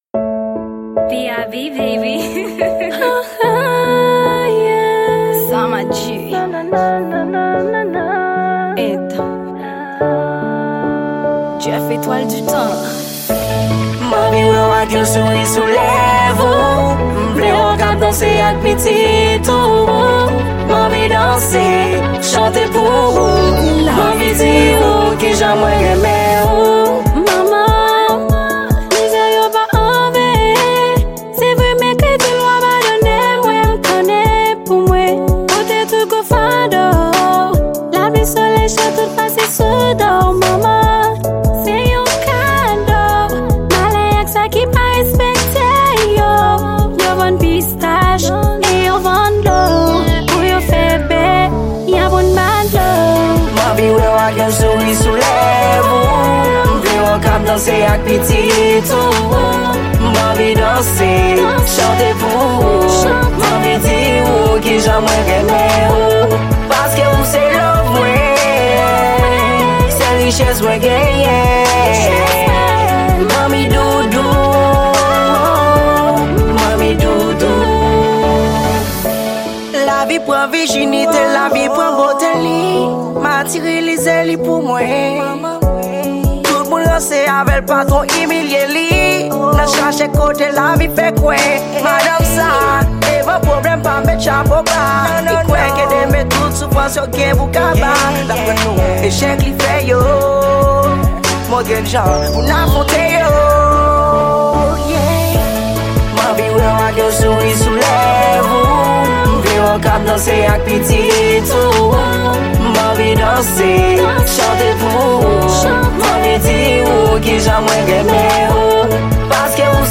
Genre: R&B